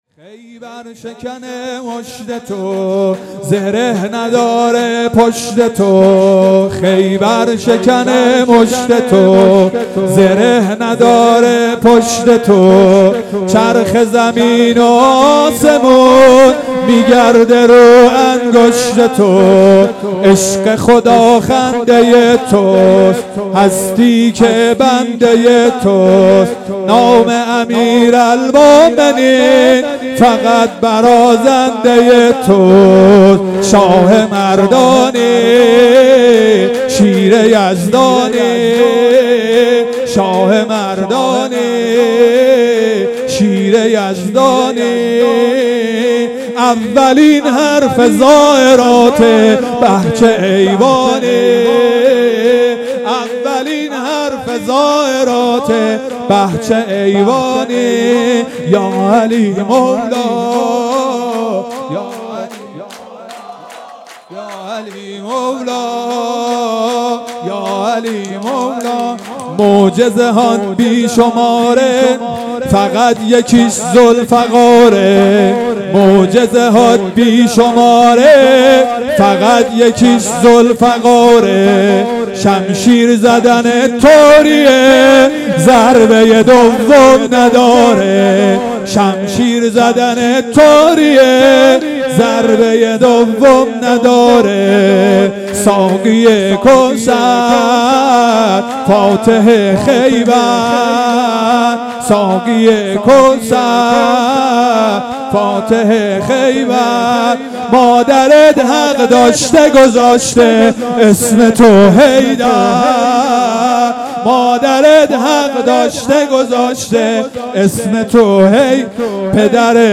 جشن ولادت حضرت عباس علیه السلام